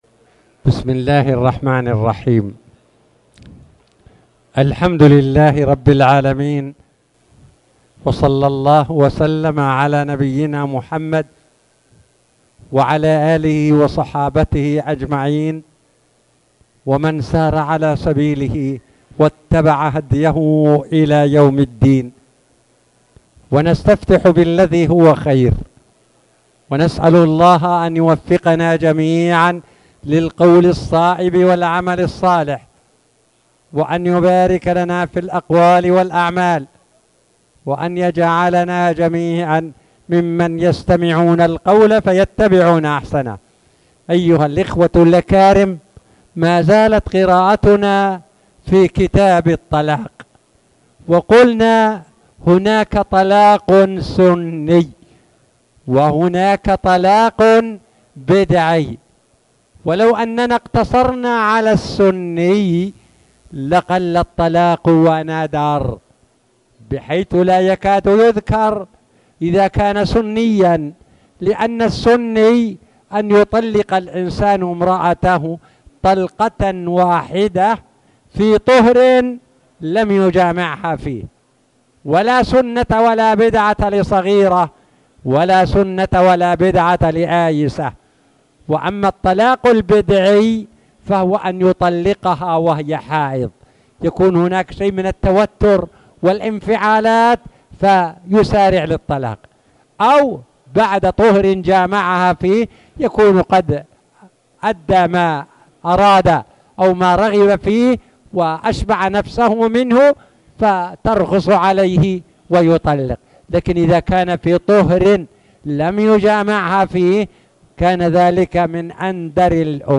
تاريخ النشر ٢٢ رجب ١٤٣٨ هـ المكان: المسجد الحرام الشيخ